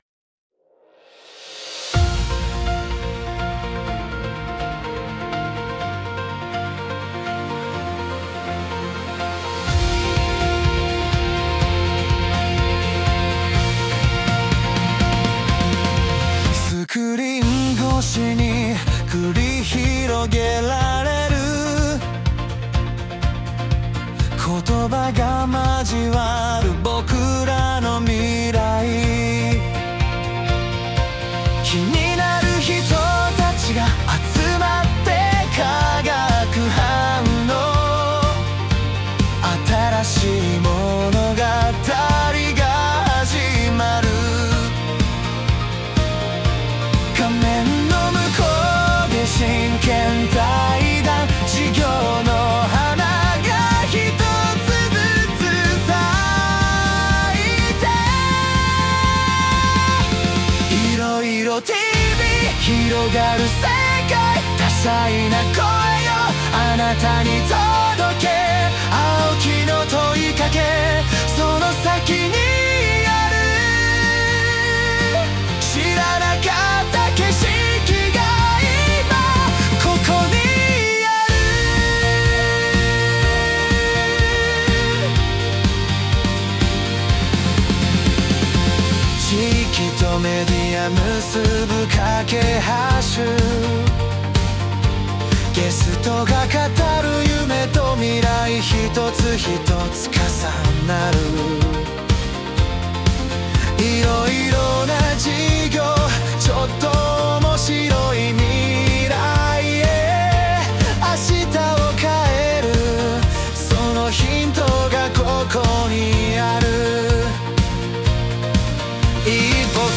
今回は、SUNOというテキストから音楽を生成するAIサービスを活用します。